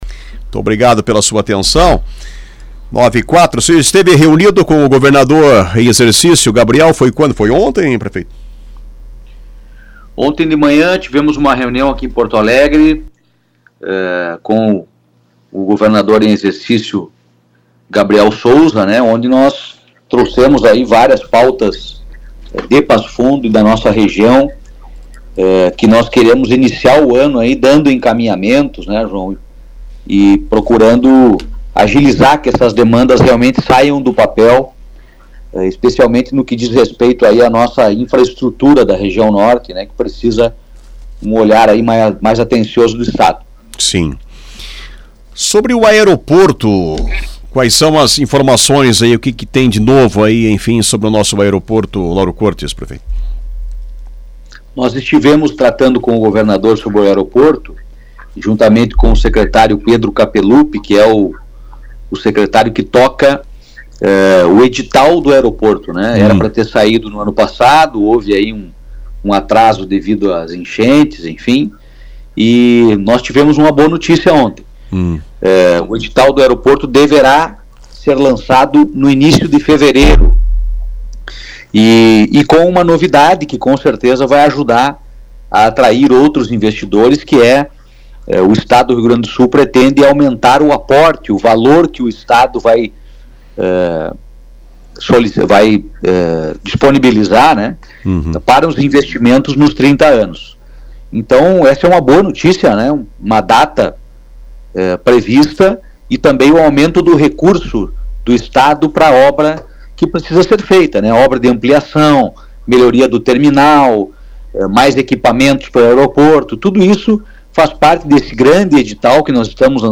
O prefeito Pedro Almeida, de Passo Fundo, foi entrevistado na manhã desta quinta-feira, 09, no programa Na Ordem do Dia, da Rádio Planalto News (92.1).